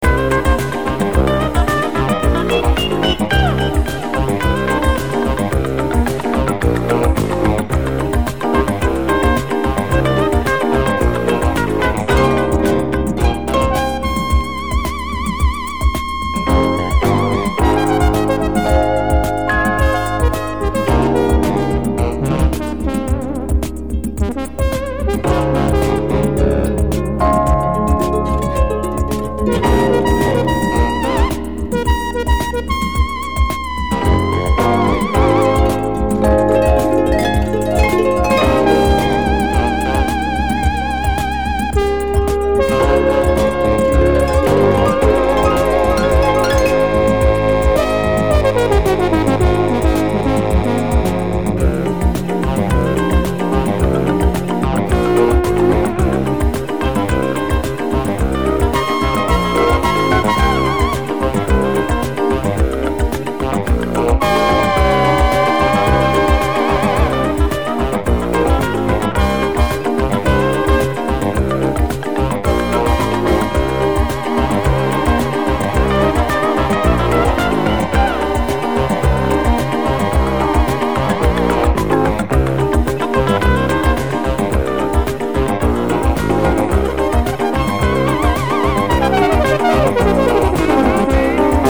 Disco Fusion Garage Classic Jazz Funk